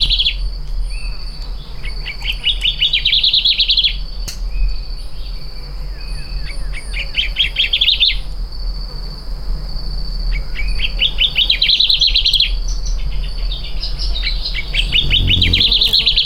Arañero Coronado Grande (Myiothlypis bivittata)
Nombre en inglés: Two-banded Warbler
Fase de la vida: Adulto
Condición: Silvestre
Certeza: Observada, Vocalización Grabada
aranero-coronado-grande.mp3